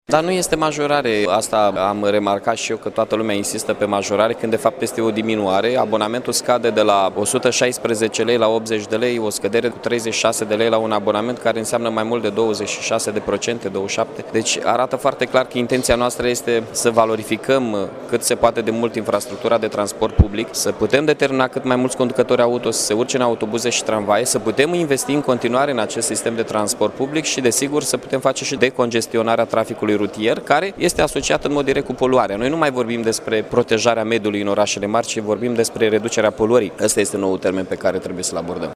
Primarul Mihai Chirica a adăugat că se introduce abonamentul unic care va fi vândut cu 80 de lei, mai ieftin cu 36 de lei, faţă de actualele tipuri de abonamente.
De la 1 septembrie, ocălătorie va costa 2,5 lei, faţă de 2 lei, cât este în prezent – a mai adăugat Mihai Chirica: